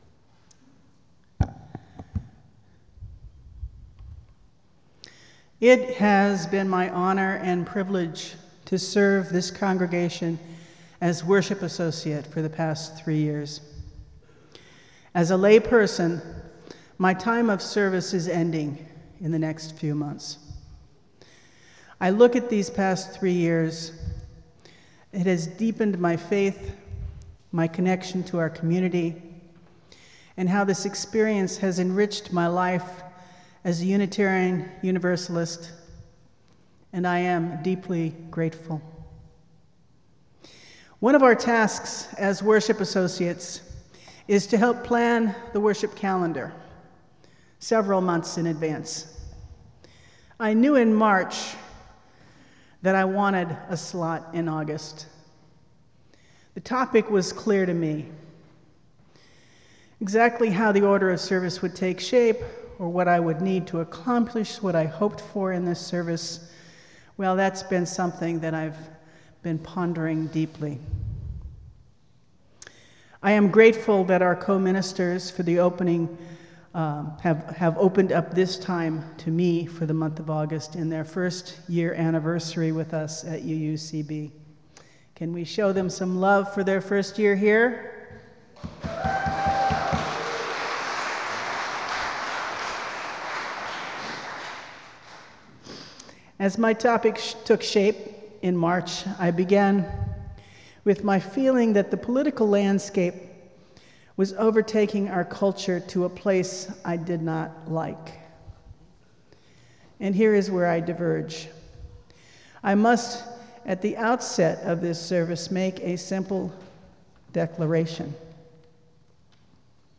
Emmett Till - Beyond the Horizon - Unitarian Universalist Church of Berkeley